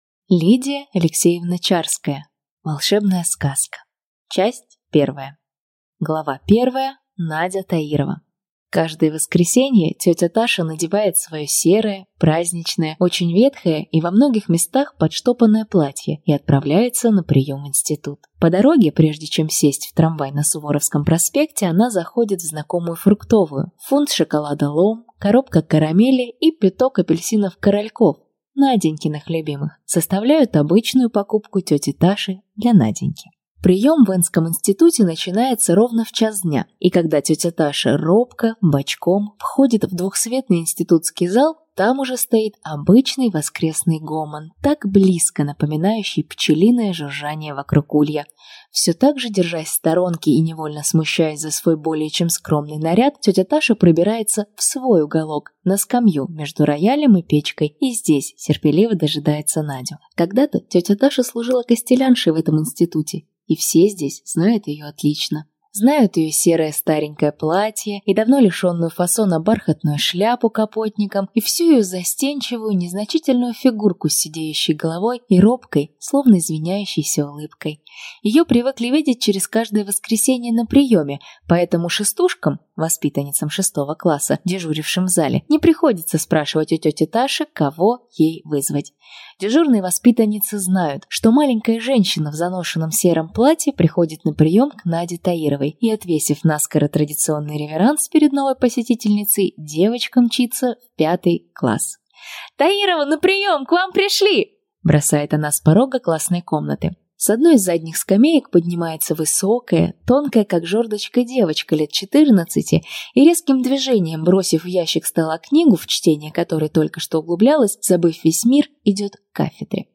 Аудиокнига Волшебная сказка | Библиотека аудиокниг
Прослушать и бесплатно скачать фрагмент аудиокниги